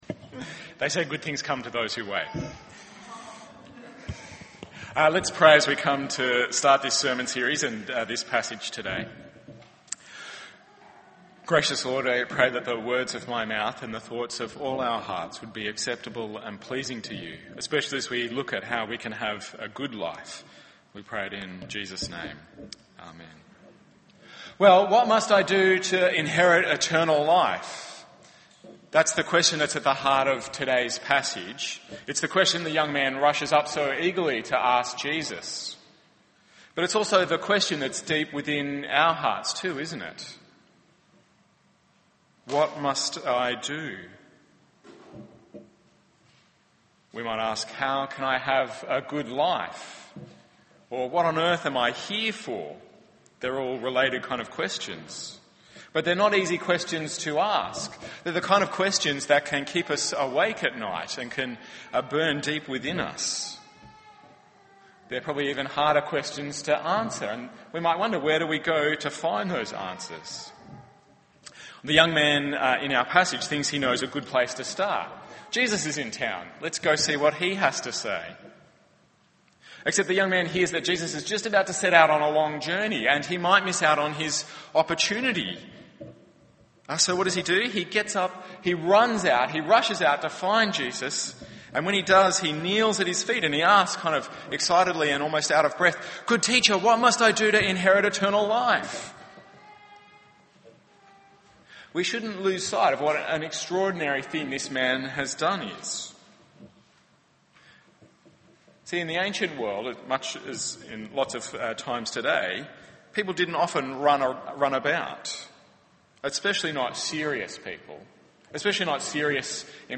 Sermons, etc.